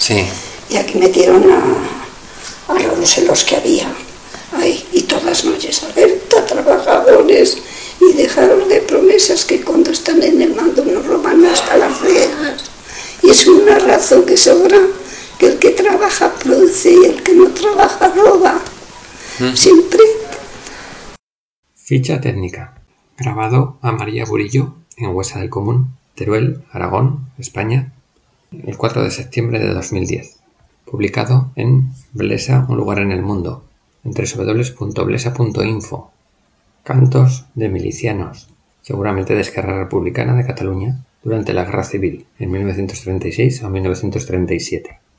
• (1936/1937) · Cantos de milicianos (cantado por una de las niñas de Huesa)
(Recopilado en Huesa del Común)